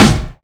kits/RZA/Snares/WTC_SNR (32).wav at 32ed3054e8f0d31248a29e788f53465e3ccbe498